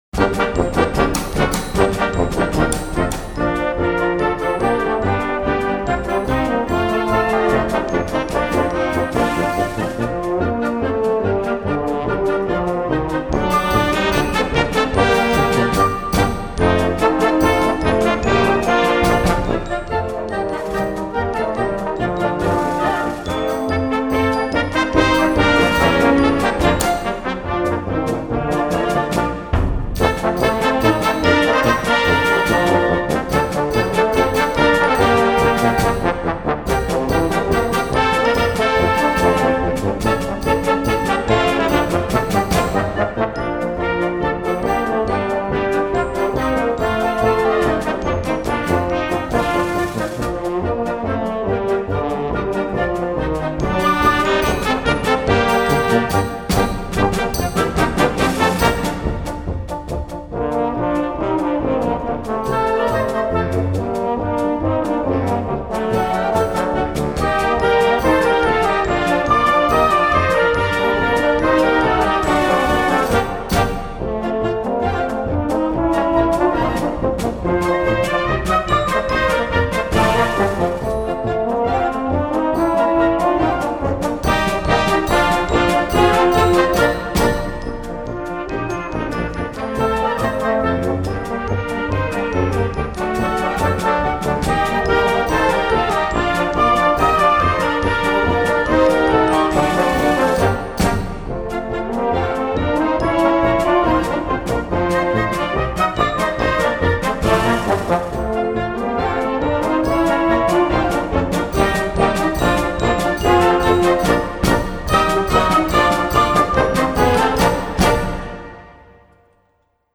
Gattung: Polka für Jugendblasorchester
Besetzung: Blasorchester